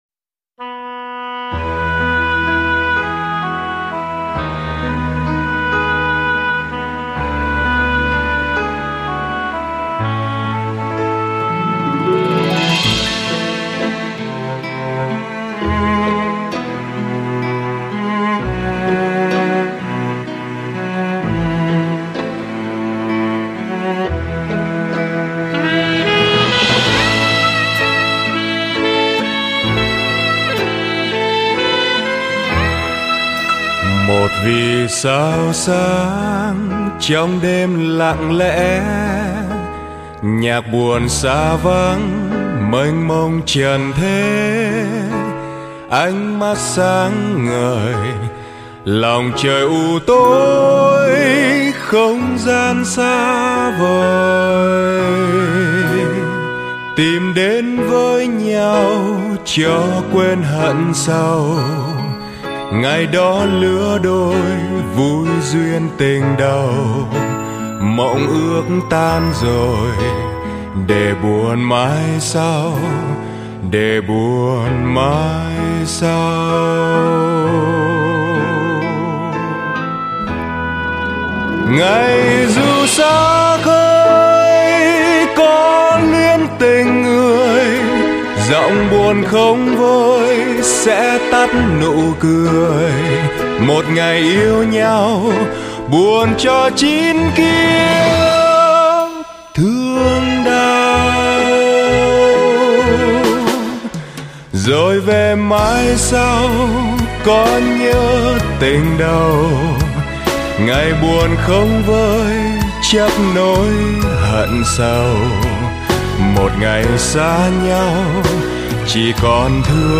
với giai điệu dìu dặt, lãng đãng